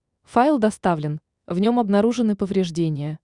голосовой помощник